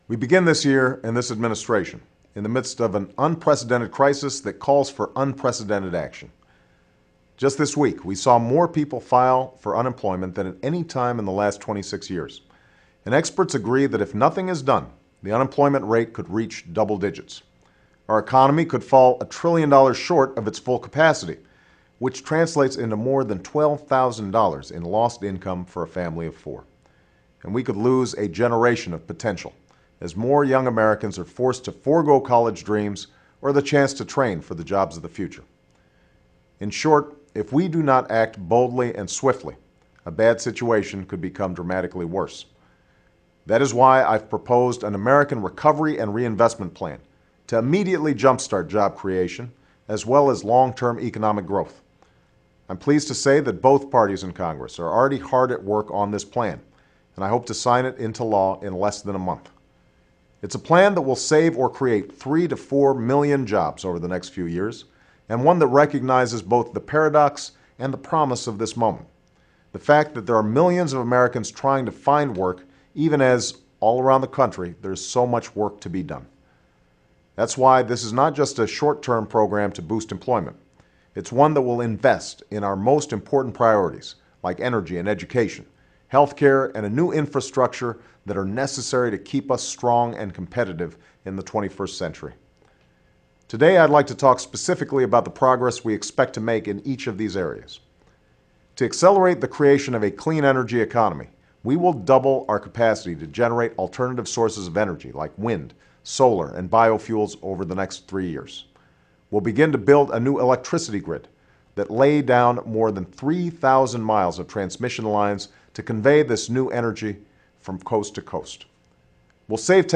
U.S. President Barack Obama gives his first weekly address
Barack Obama gives his first weekly address as President of the United States. President Obama discusses the installation of the American Recovery and Reinvestment Plan to jump-start the economy.
Broadcast on C-SPAN, Jan. 24, 2009.